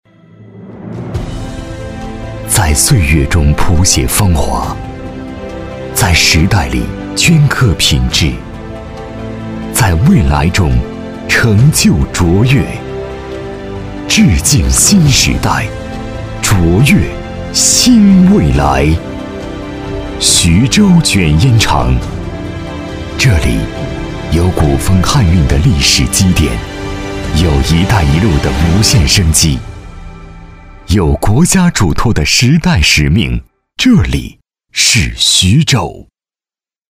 男175号